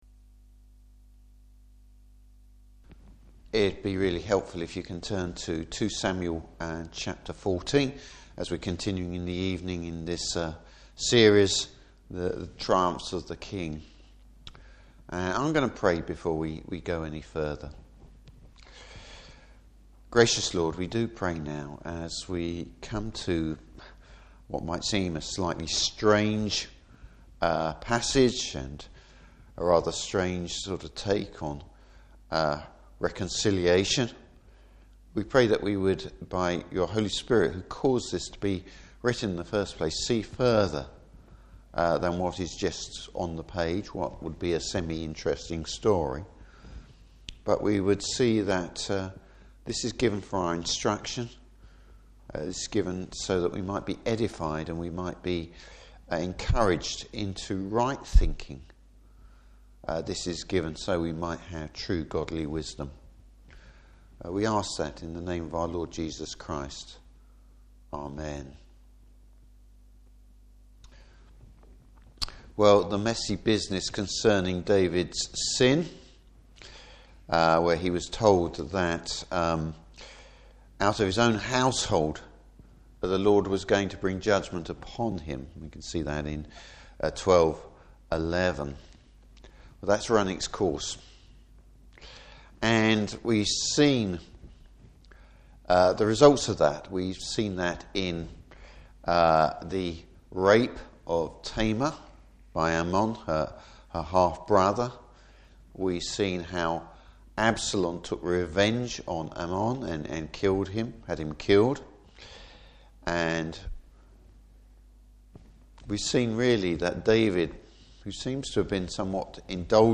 Service Type: Evening Service Whose interest is Joab acting in?